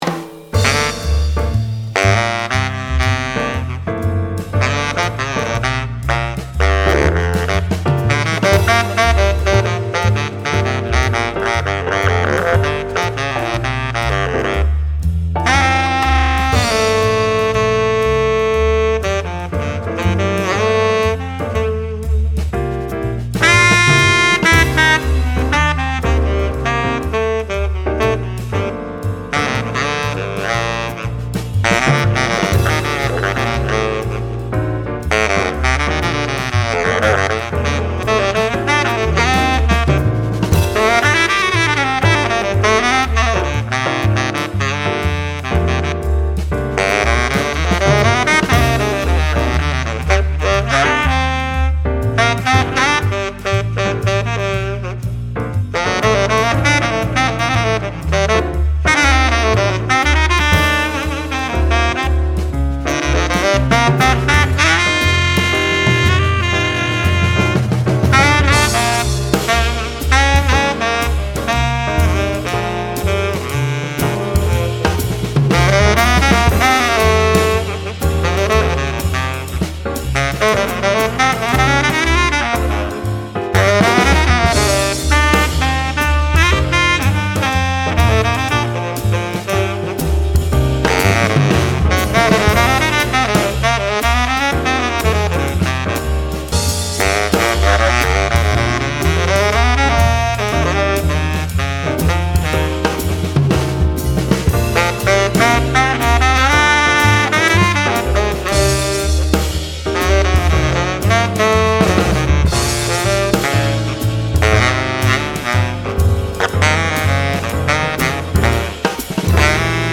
音の特徴としては、中央にフォーカスし、音が太く倍音が多い点が挙げられます。
音色と特徴渋め、響きがある、吹きやすい、演奏者の好みの音が出る、 中央フォーカス、パワフル
スタイル：ジャズ
Baritone